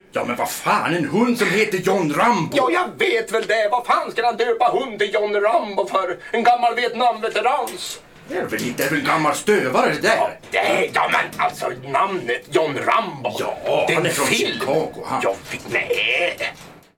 Ringsignaler från original ljudspår
Ringsignaler ur filmen Småstadsliv 3